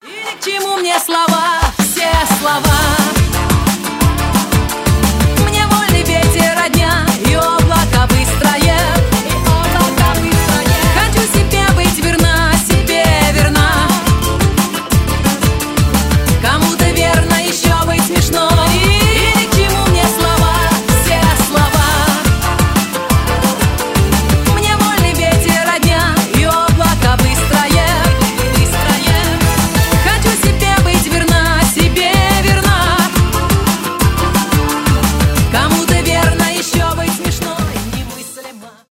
поп , шансон